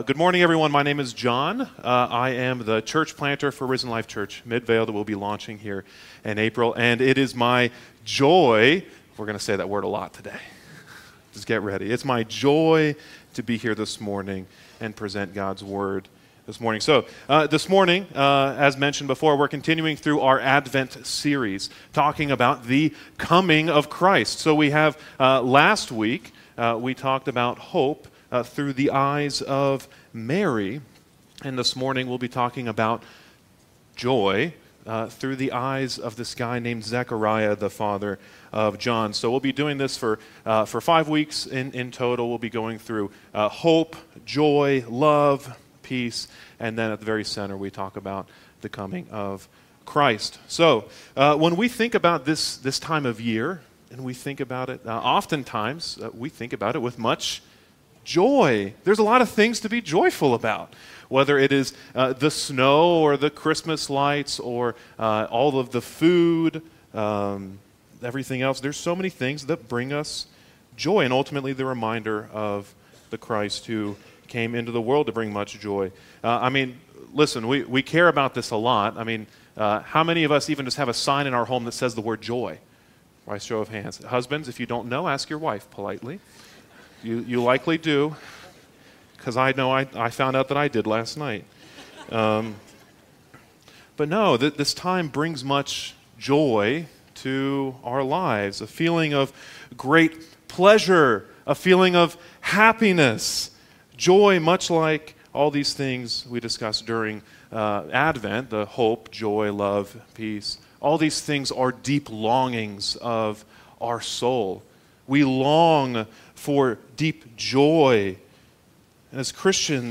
Join us as we celebrate the birth of our savior Jesus with our annual Christmas Eve service.